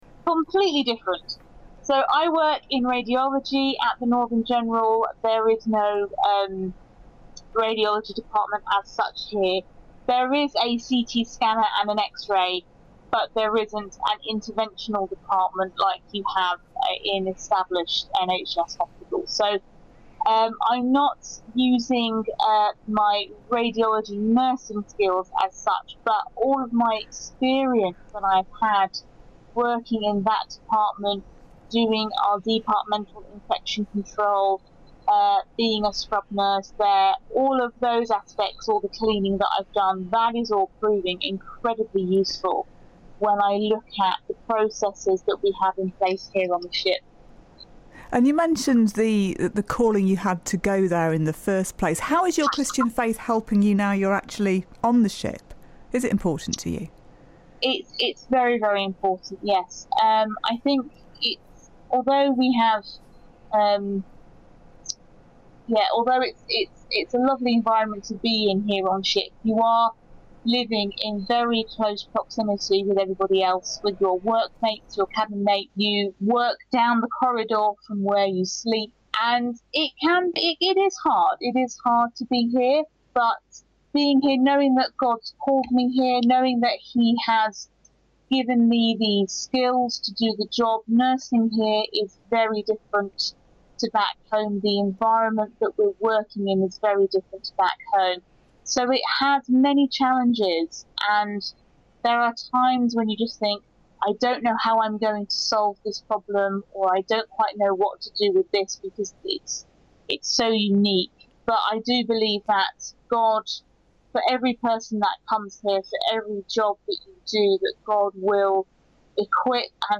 As broadcast on BBC Radio Sheffield's Early Sunday Breakfast Show, Sunday 24th September 2017.